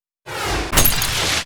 battle_item_blizzard.mp3